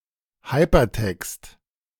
Hypertext (IPA: ˈhaɪ̯pɐˌtɛkst,
De-Hypertext.ogg.mp3